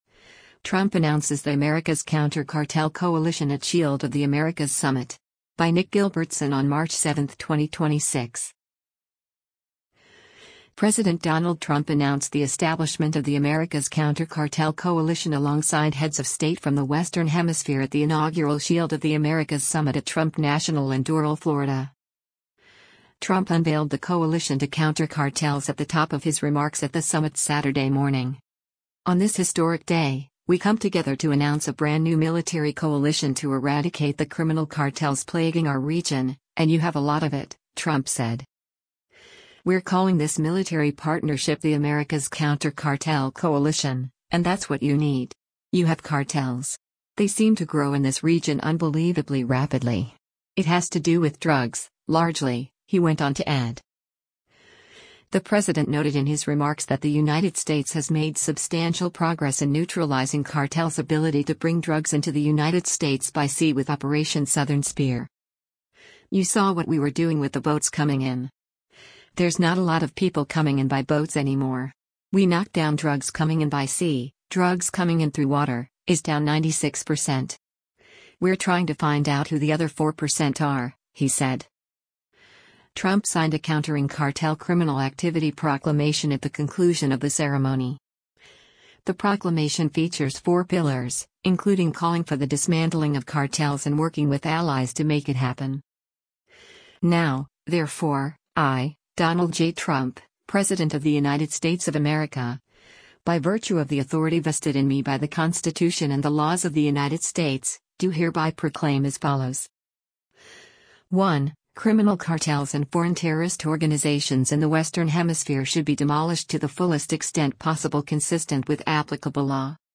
Trump unveiled the coalition to counter cartels at the top of his remarks at the summit Saturday morning.